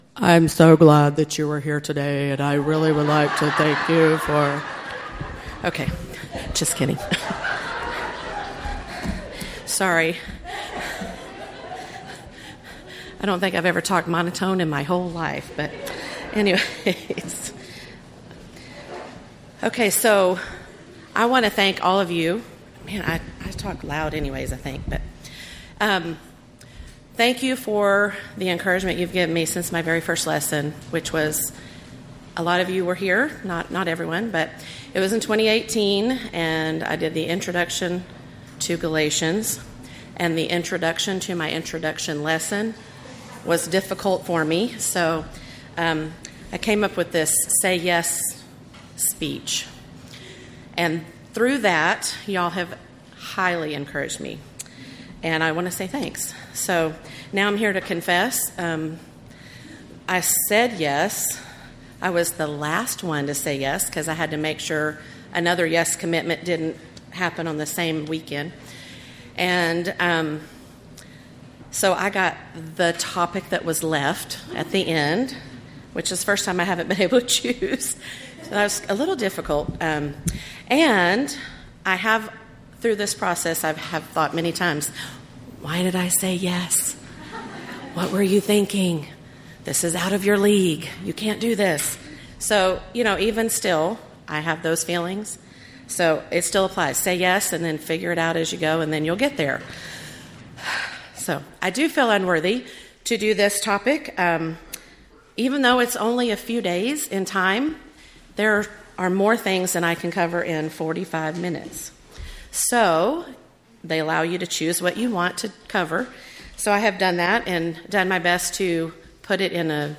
Event: 11th Annual Texas Ladies in Christ Retreat Theme/Title: The Death, Burial, and Resurrection of Christ